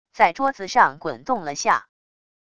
在桌子上滚动了下wav音频